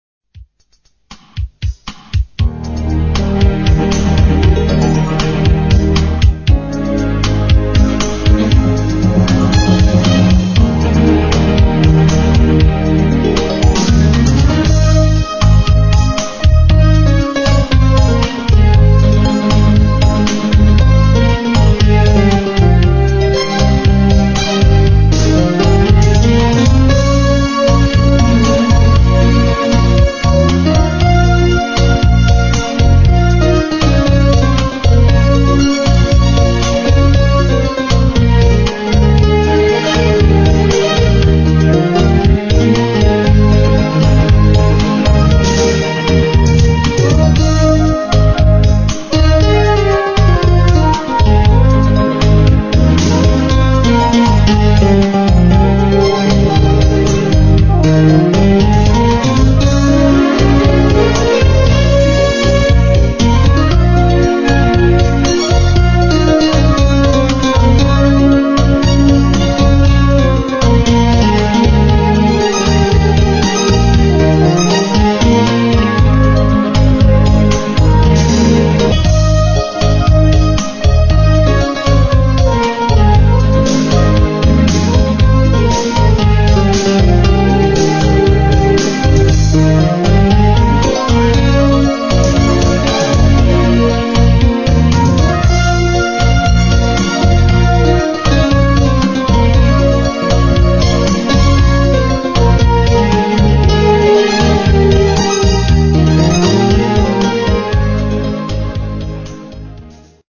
musique instrumentale